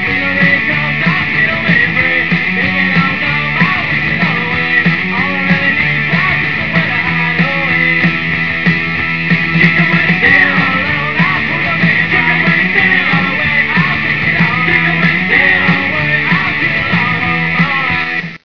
Unfortunately, the clips are not of best quality.